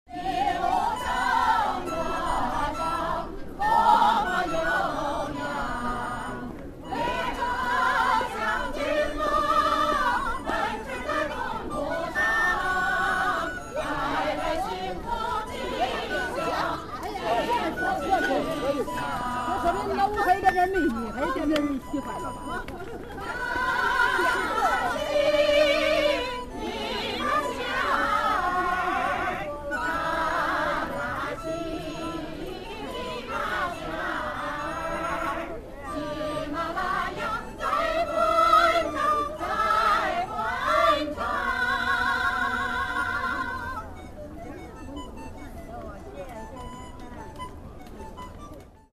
These recordings were taken mostly in Beijing, in the spring 2007 – the Great Wall implied a four-hour bus drive.
01. Airport 6AM
Field Recording Series by Gruenrekorder
airport_6am.mp3